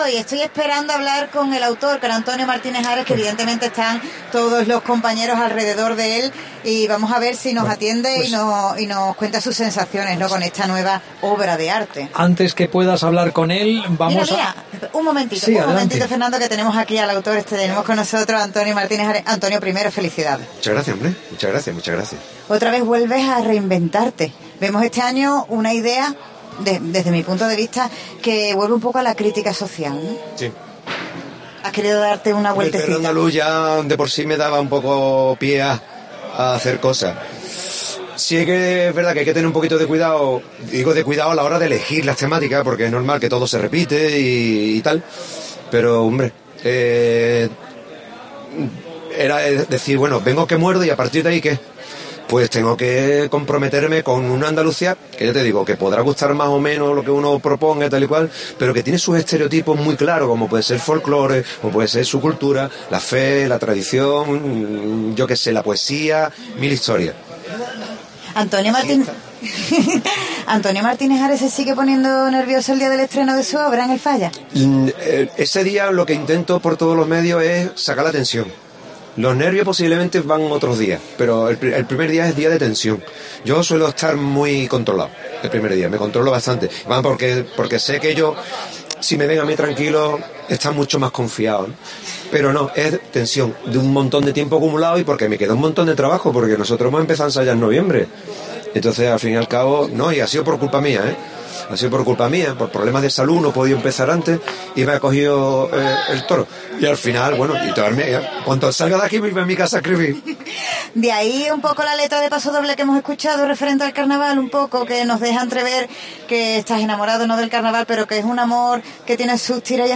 AUDIO: Escucha la entrevista al autor de la comparsa 'El Perro Andalú' tras su paso por clasificatorias